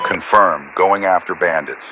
Radio-pilotWingmanEngageAir4.ogg